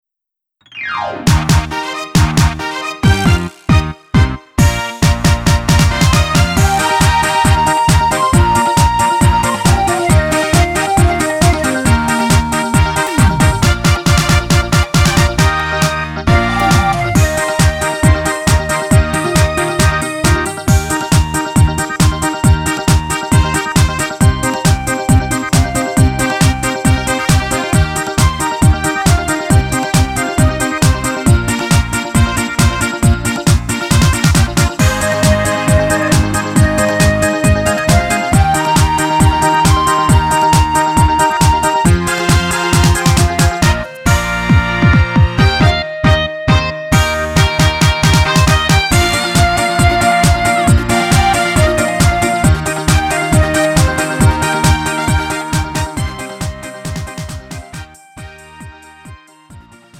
음정 원키 3:29
장르 구분 Lite MR